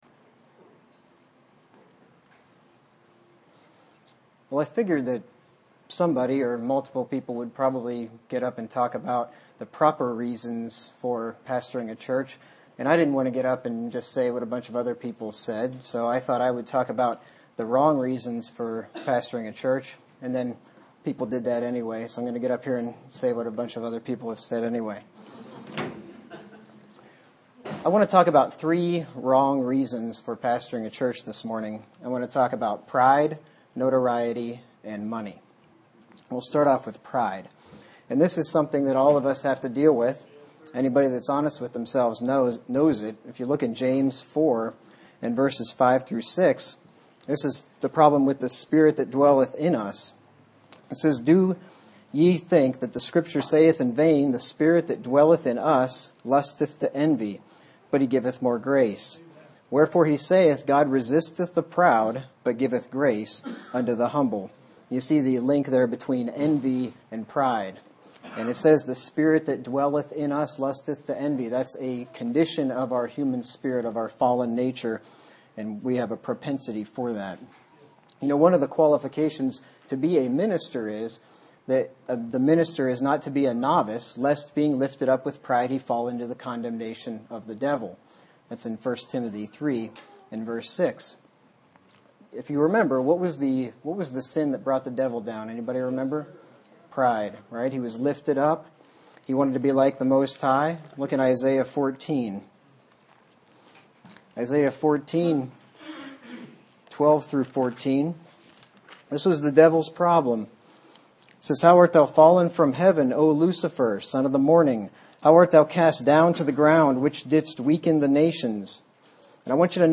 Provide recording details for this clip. Format: MP3 Mono 22 kHz 30 Kbps (ABR)